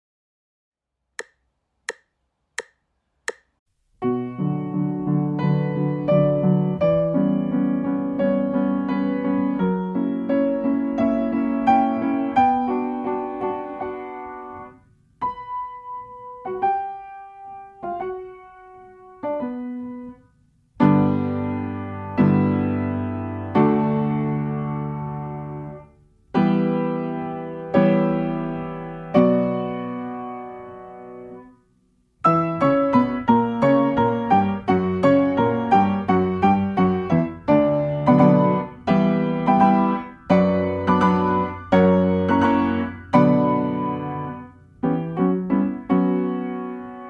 akompaniamentu pianina
1 wersja: 86 bmp
Nagrania dokonane na pianinie Yamaha P2, strój 440Hz
piano